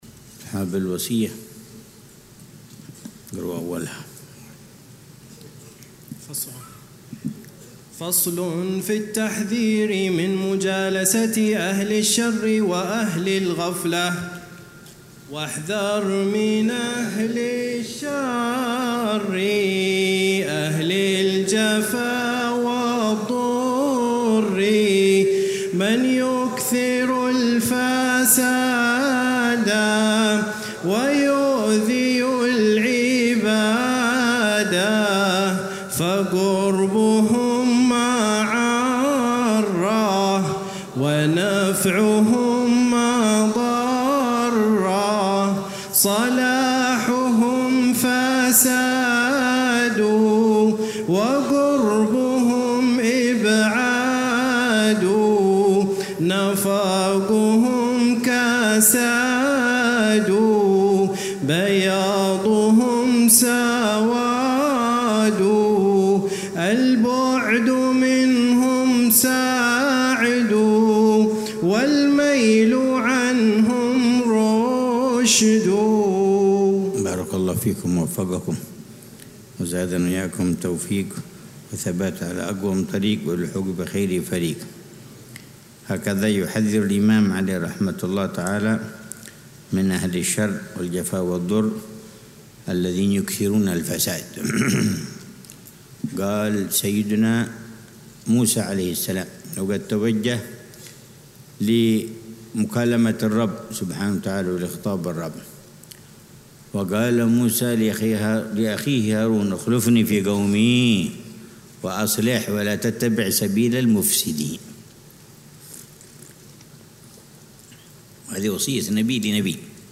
الدرس الحادي عشر (22 محرم 1447هـ)